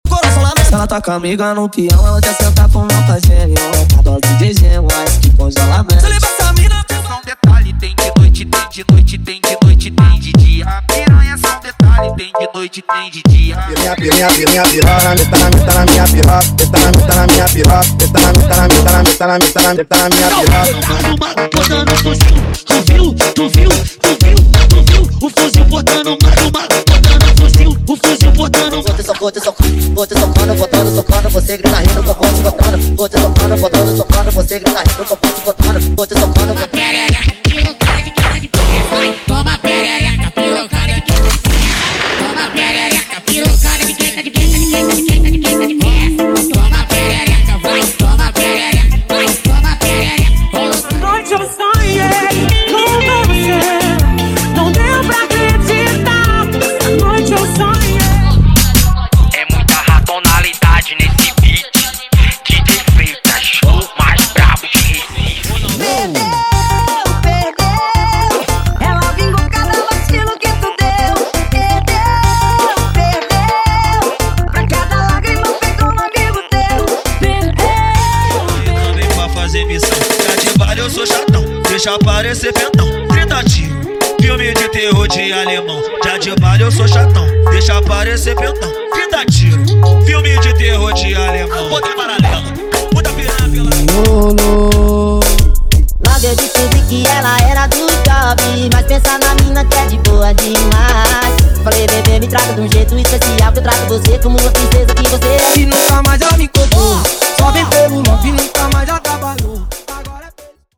• Brega Funk = 70 Músicas
• Sem Vinhetas
• Em Alta Qualidade